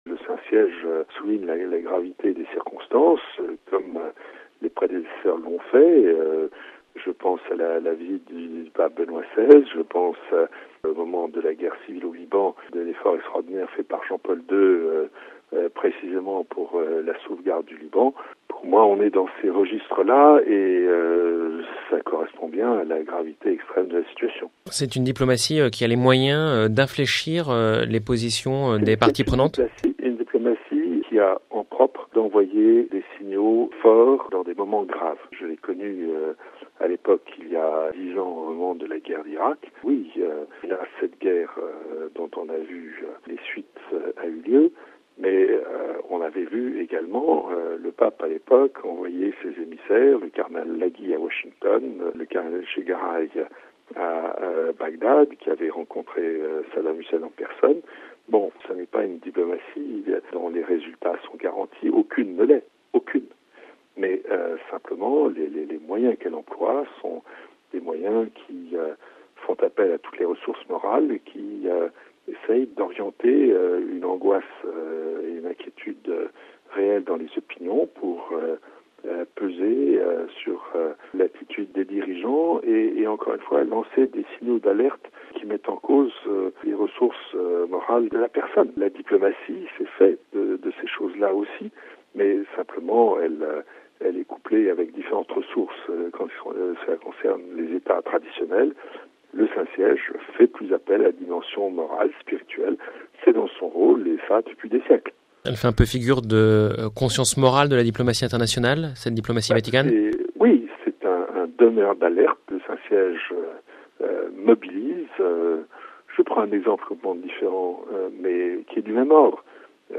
Des propos recueillis par